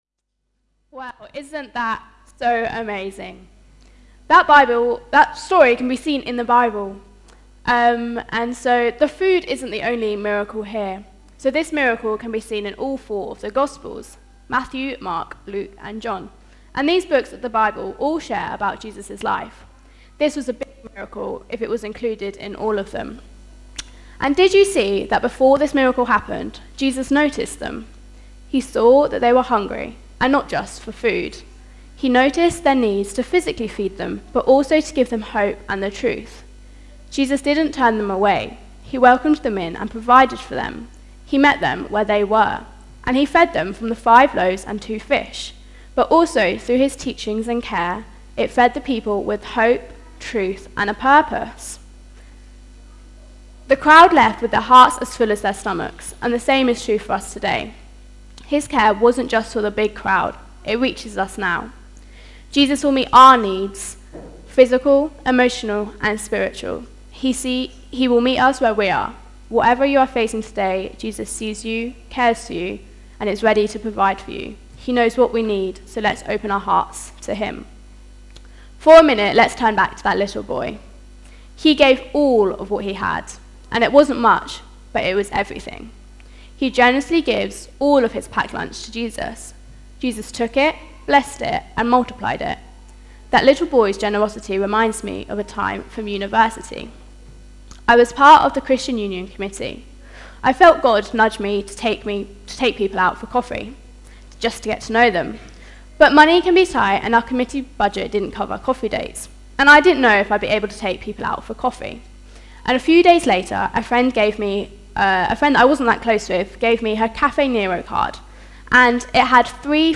5th-October-2025-All-Age-Harvest-Service-sermon.mp3